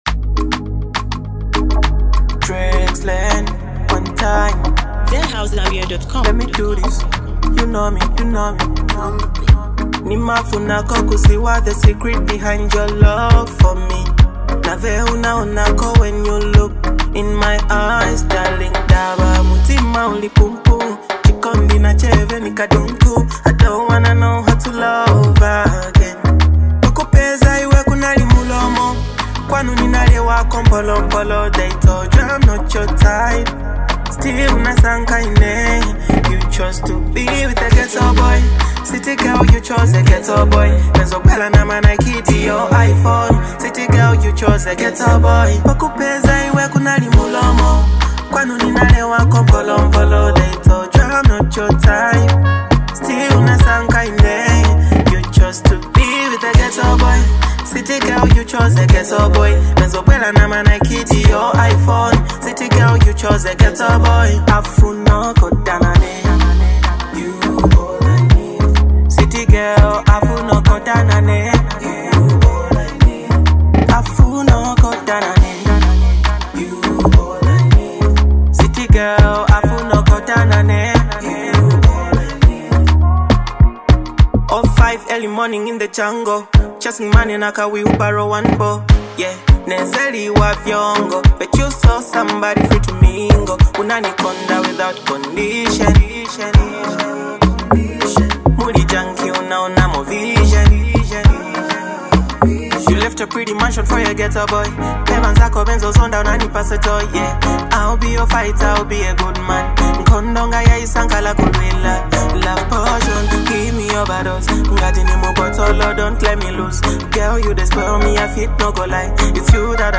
pure Afro vibes
a heartfelt jam that celebrates love beyond status.